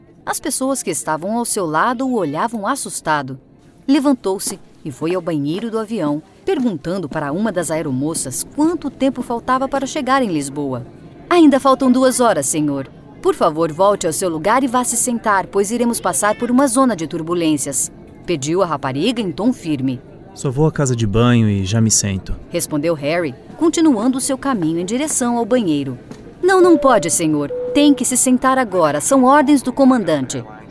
My professional and well-equipped Home Studio provides me with the ability to offer a QUICK turnaround to clients around the world, whenever needed.
✦Warm, soft, low-pitched, friendly, excellent diction, trusted voice.